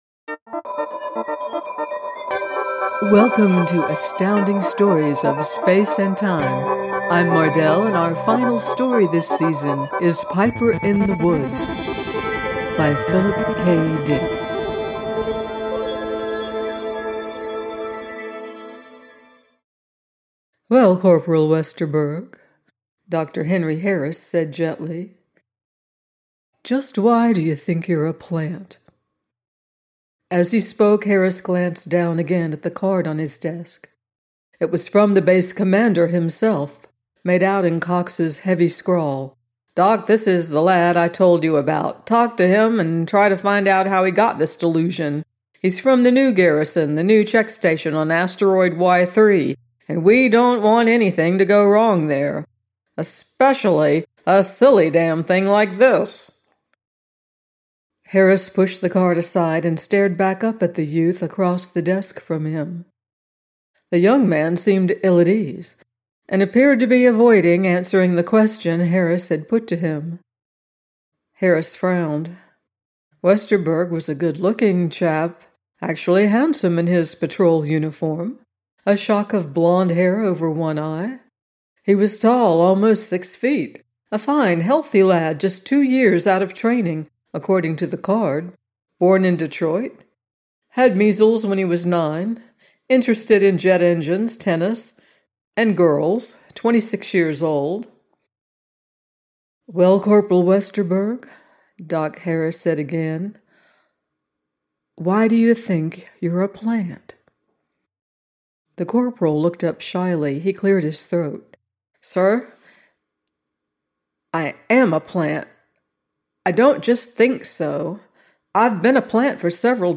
Piper in the Woods – by Philip K. Dick - audiobook